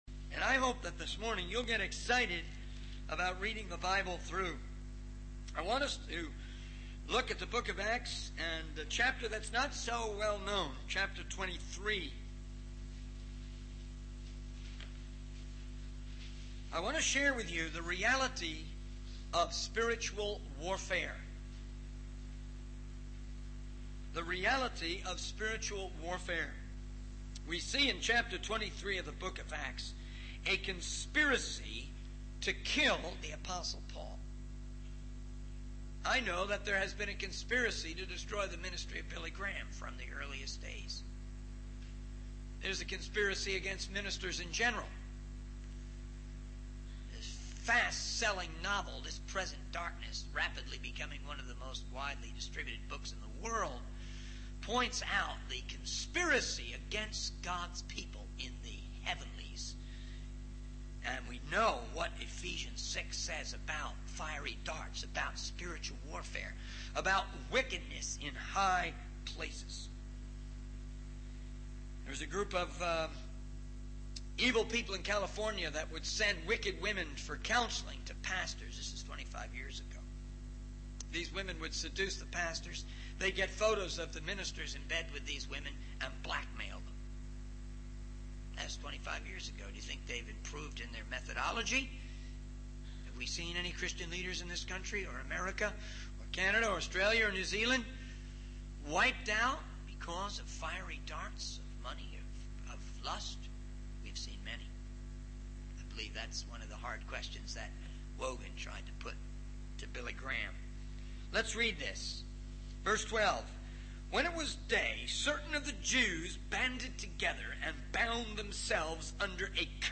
In this sermon, the speaker discusses the attempts of Satan to destroy young believers who have recently made professions of faith. The speaker emphasizes the importance of repentance, seeking forgiveness from the Lord, meditating on scripture, and praising God as ways to combat discouragement and depression. The speaker also encourages the congregation to prioritize prayer, repentance, and unity in order to effectively combat the challenges they may face in their spiritual journey.